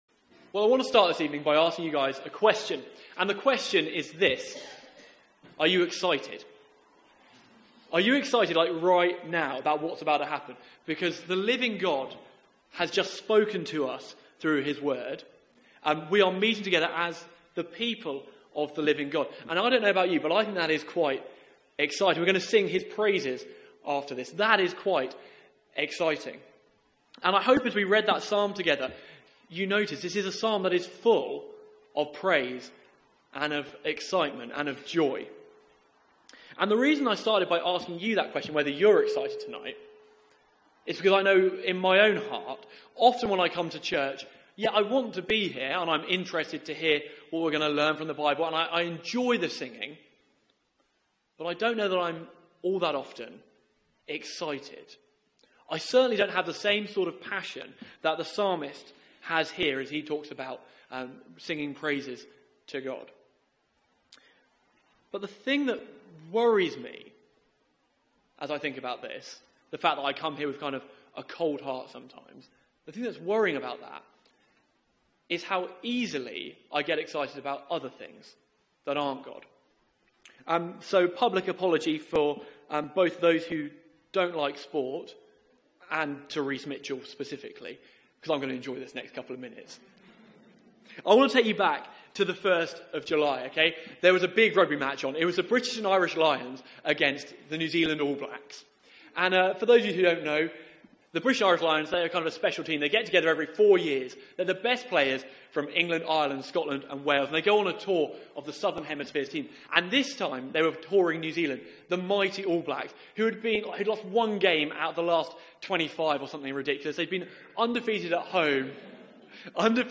Media for 6:30pm Service on Sun 20th Aug 2017 18:30 Speaker
Series: Summer Psalms Theme: King of all the earth Sermon Search the media library There are recordings here going back several years.